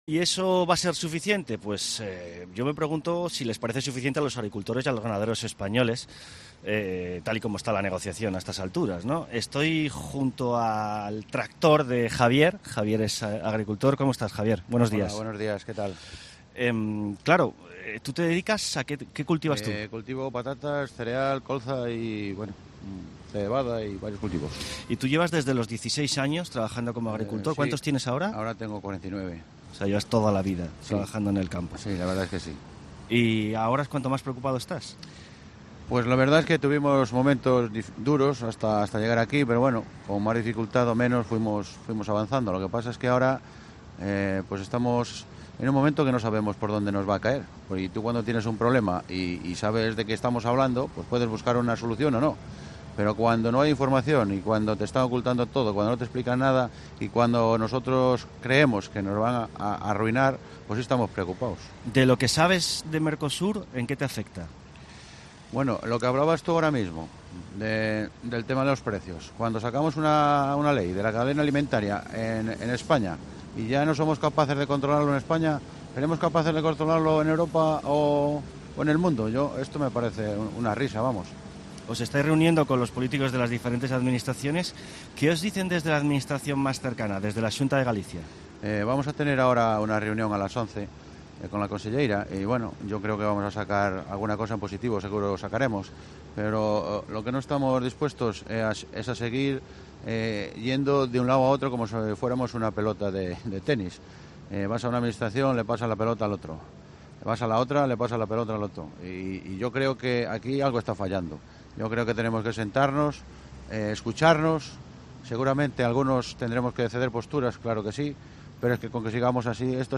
La entrevista de las 9H